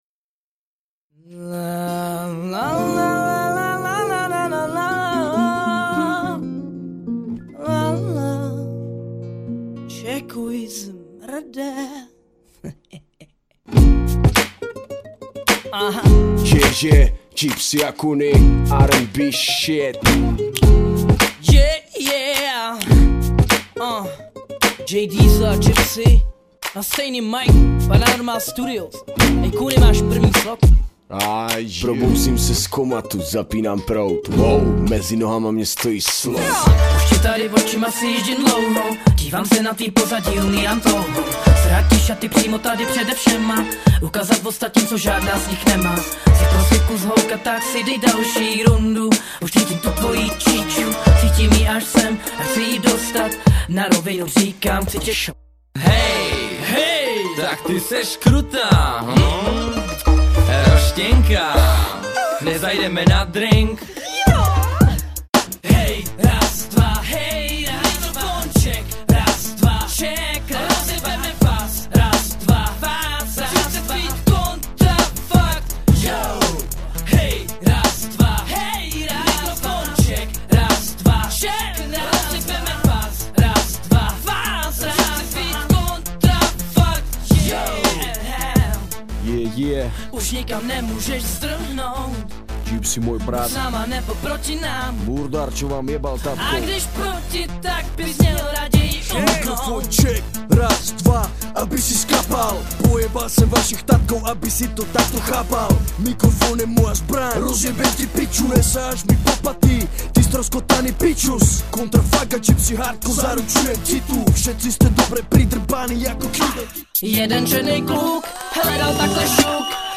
Sampler